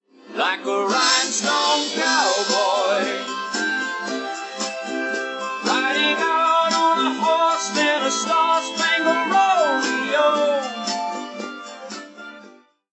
Vocal isolation using Audacity’s “Vocal Reduction and Isolation” effect.
Settings: “Isolate Vocals”, 10, 200, 8000